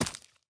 immersive-sounds / sound / footsteps / resources / ore-01.ogg